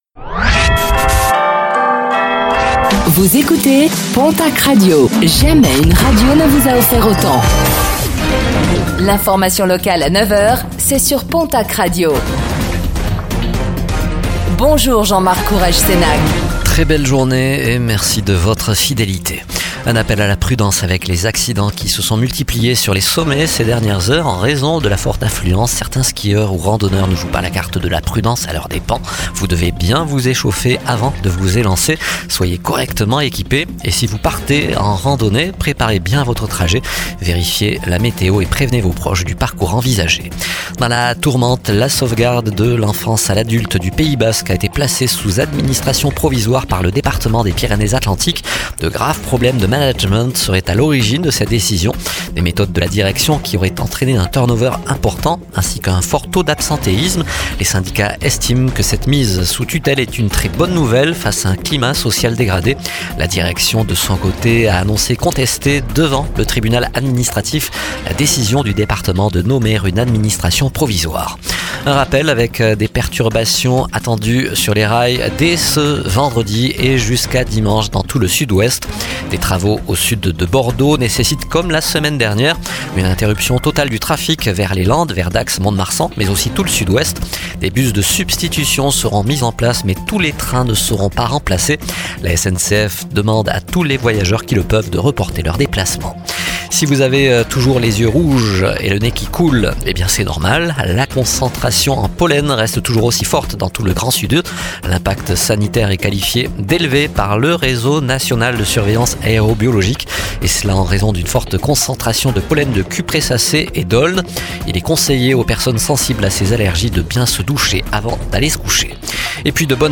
09:05 Écouter le podcast Télécharger le podcast Réécoutez le flash d'information locale de ce mercredi 26 février 2025